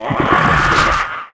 hlisk_dead.wav